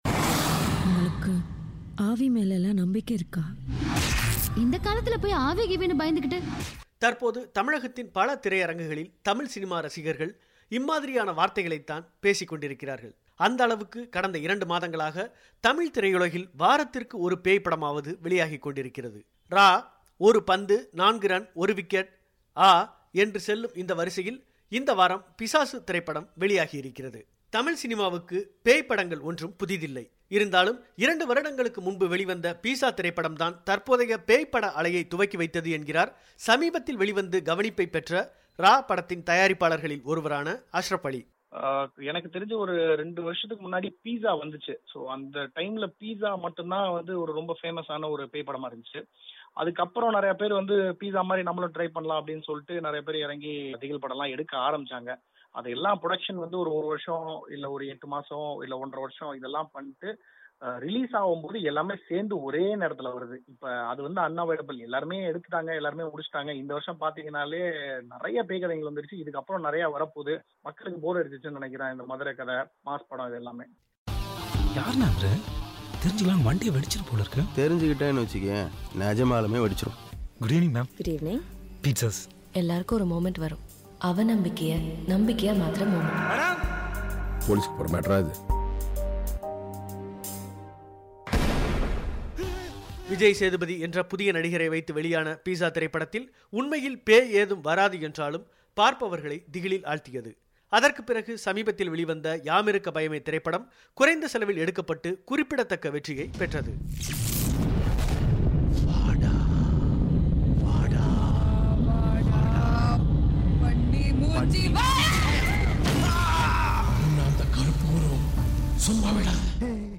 இந்த பேய் தமிழ் திரைப்பட அலை குறித்த பிபிசியின் சிறிய ஒலிப்பெட்டகம்.